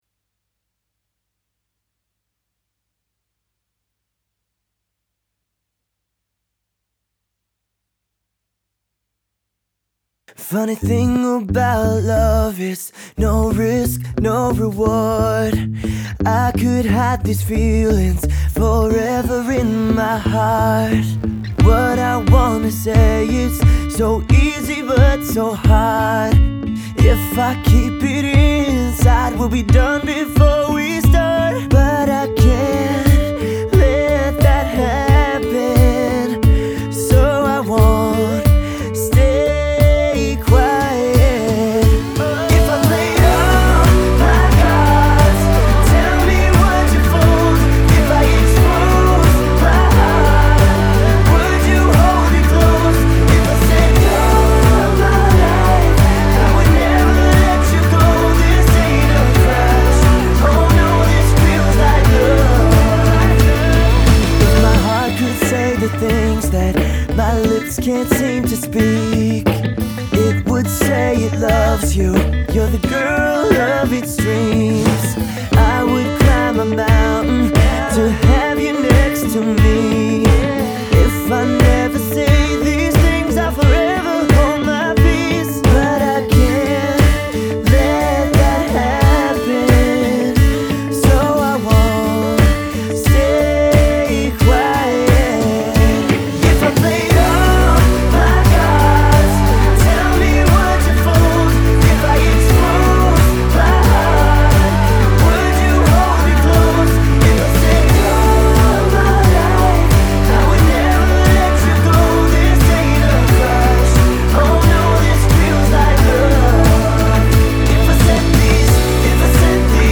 Оцените Поп-рок
Привет! Мне кажется что грязь в нижней середине из-за того что несколько гитар и пианино играют вместе и в одном регистре. Из-за этого плохо слышно акустическую гитару в нижней середине.